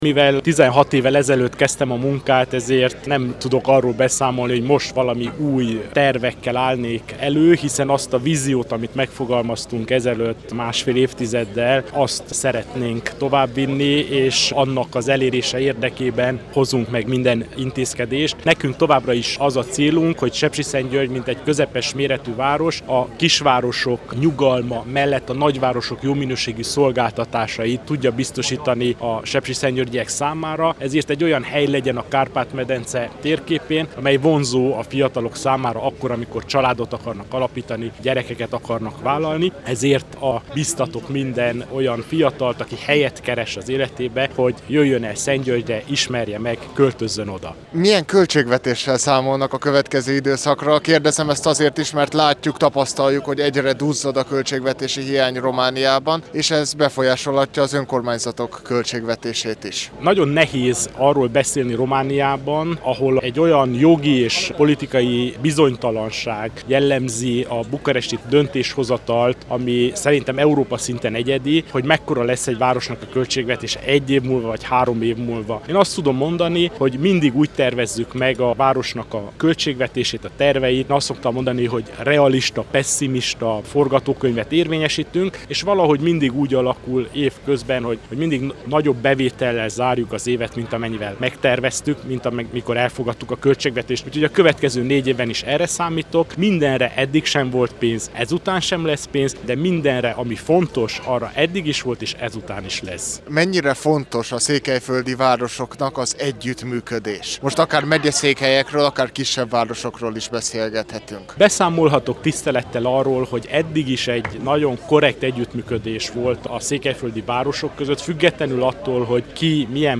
Kerekasztalbeszélgetés a székelyföldi városok polgármestereivel
A 33. Tusványoson tartott önkormányzati kerekasztal-beszélgetésen részt vett, Soós Zoltán, Marosvásárhely polgármestere, Korodi Attila, Csíkszereda polgármestere, Antal Árpád, Sepsiszentgyörgy polgármestere és Szakács-Paál István, Székelyudvarhely frissen megválasztott polgármestere.